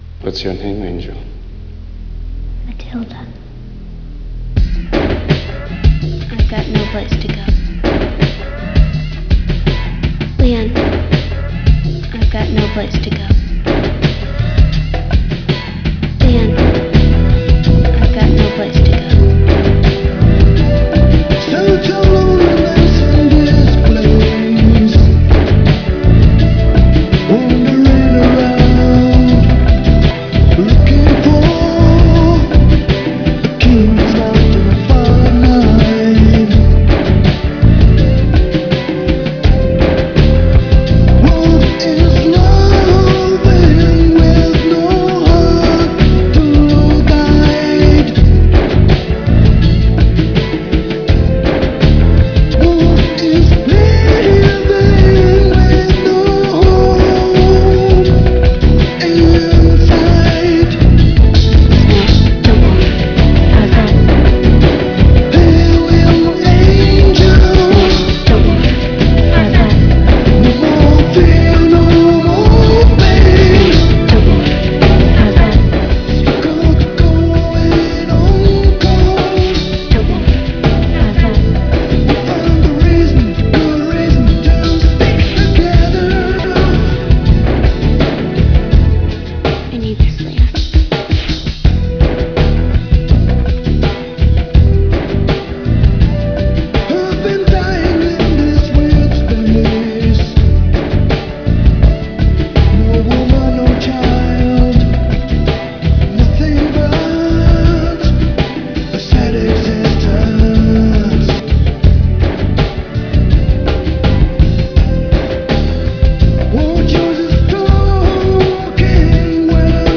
It contains dialog from the film mixed into the music.